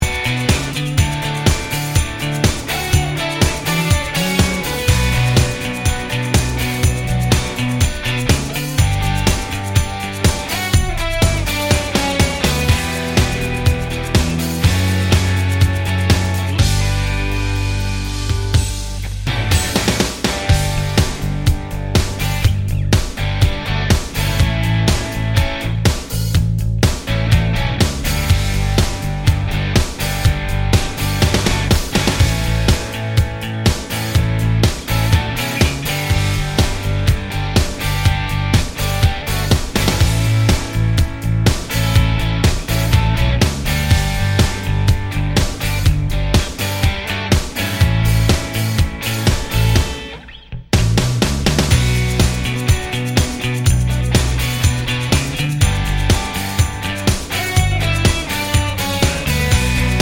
no Backing Vocals Rock 4:09 Buy £1.50